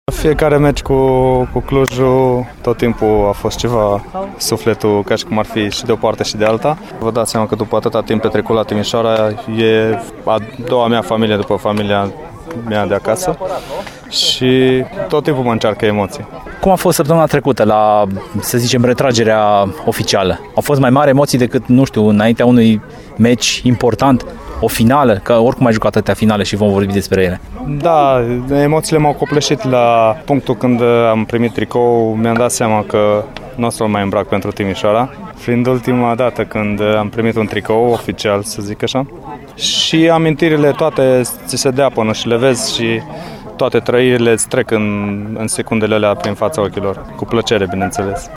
Interviul pe larg va fi duminică la emisiunea „Arena Radio”.